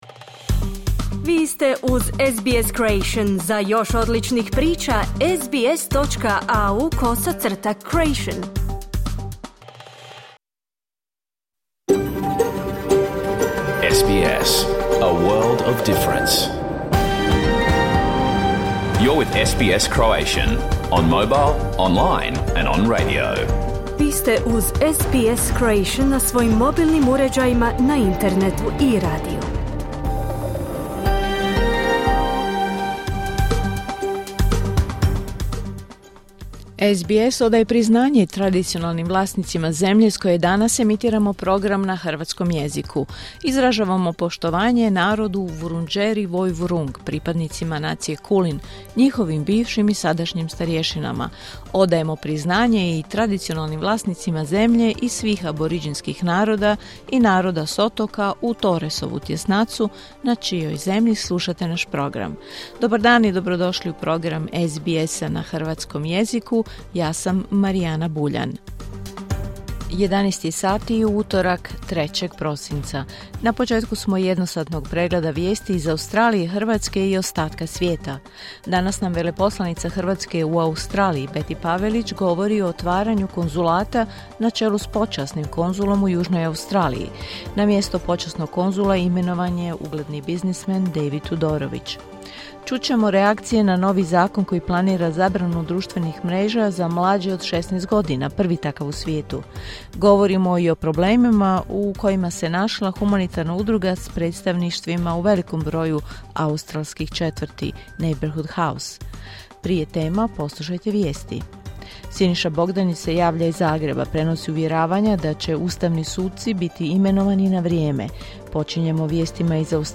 Vijesti i aktualnosti iz Australije, Hrvatske i svijeta. Emitirano na radiju SBS1 u 11 sati po istočnoaustralskom vremenu.